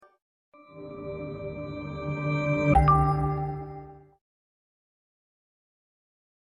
Harmonyos Boot Sound Xiaomi hyper sound effects free download
Harmonyos Boot Sound Xiaomi hyper os Surya theme